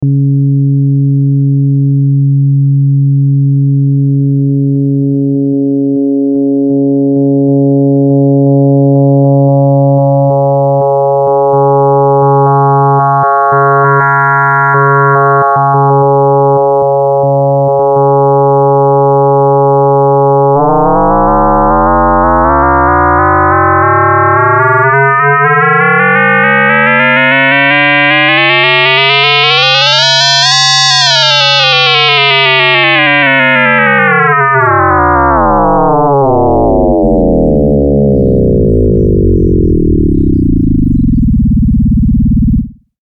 Hab gerade mal ein einfaches Beispiel mit 2 Operatoren gemacht, das sich leicht nachvollziehen lässt. Mit Assignable Knob1 kontrolliere ich die Frequenz der Modulators mit Knob2 dessen Level. Am Anfang hört man was passiert wenn ich den Level der Modulators verändere, später ändere ich dessen Frequenz, vielleicht verstehst du dann was ich meine.